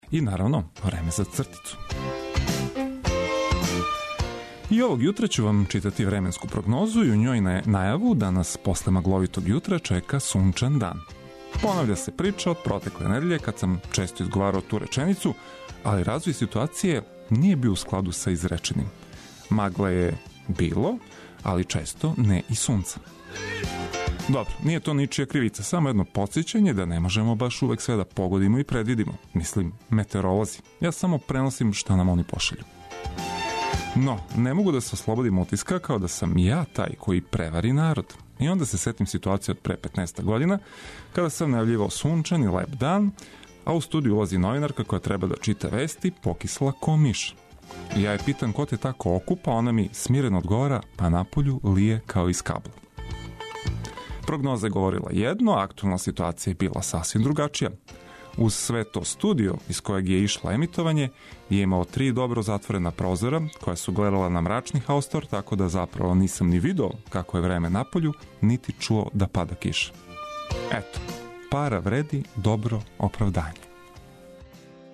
Устанак је ту да вас наоружа осмехом и најновијим информацијама за успешан почетак новог дана. Одлична музика је неопходни бонус!